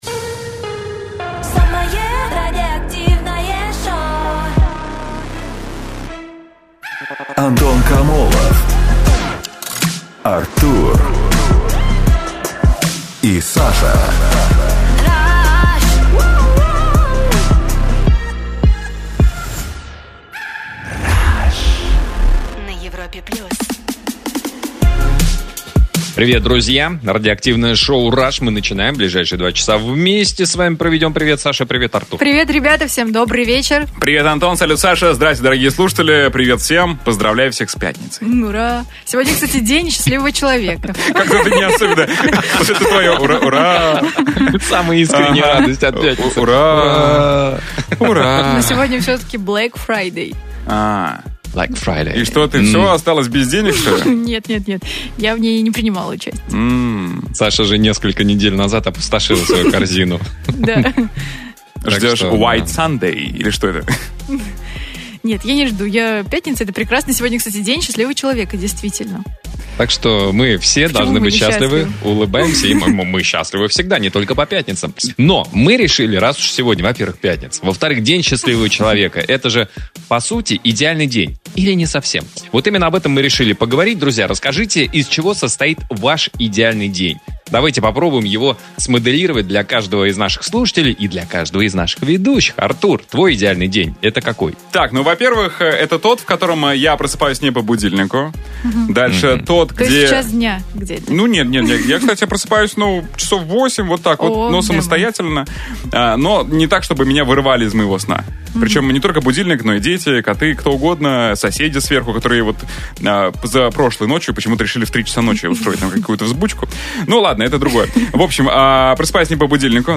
С 20:00 до 22:00 по будням на Европе Плюс гарантированы горячие споры, неизбитые шутки и отборные темы для обсуждения.